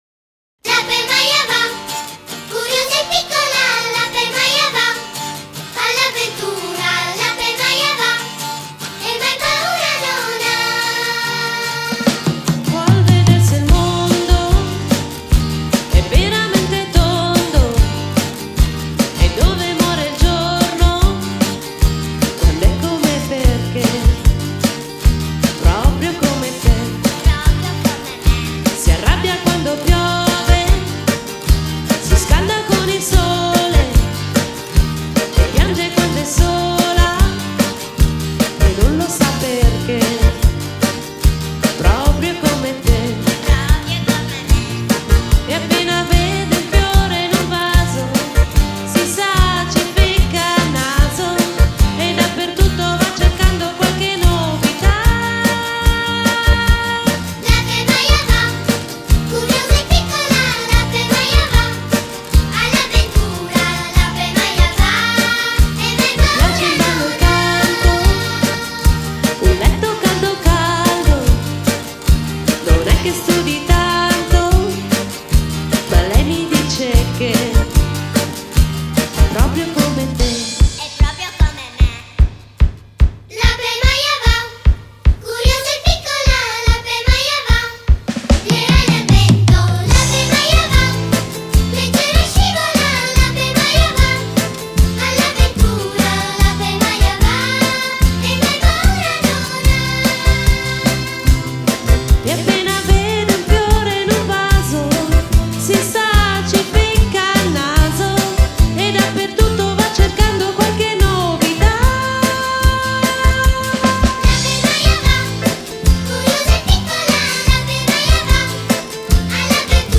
voce solista
batteria
basso
tastiere
chitarre
adorabili cori bambini
cori adulti (si fa per dire) maschili
cori adulti femminili